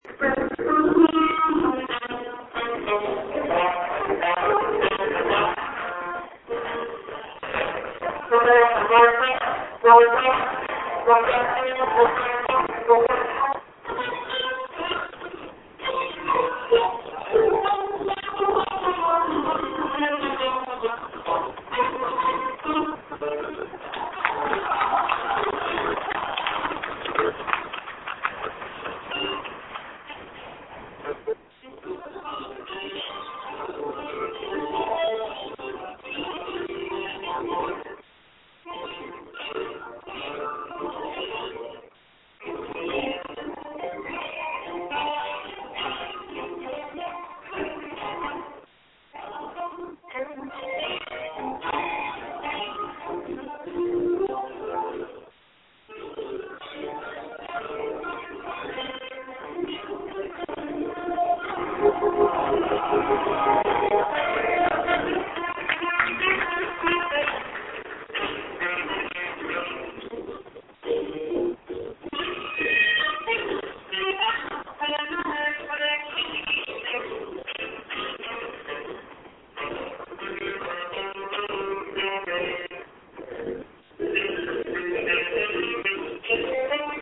A little Jazz…